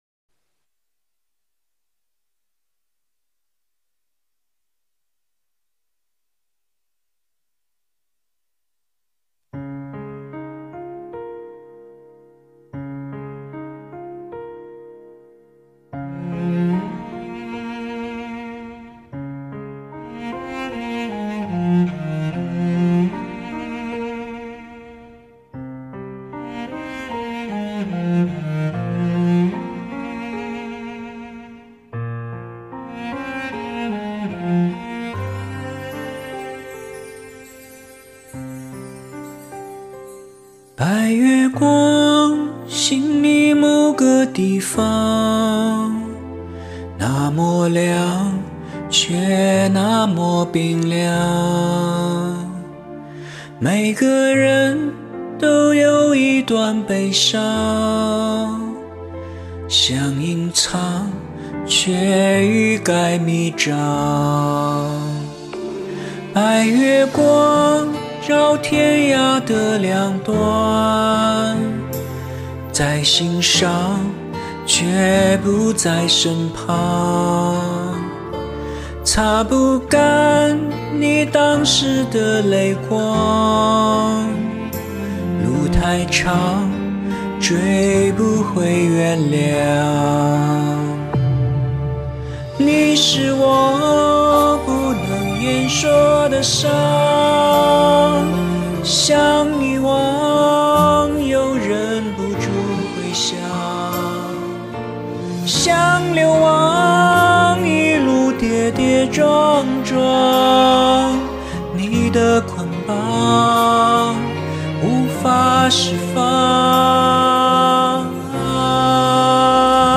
• 月色一样的凉和亮，悠远伤感。
我喜欢你这种不加echo显真功的录法。
手机加话筒的录法，简单并快乐着：)
好干净的声音